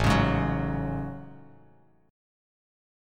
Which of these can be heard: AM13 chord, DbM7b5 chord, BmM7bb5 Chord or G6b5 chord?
AM13 chord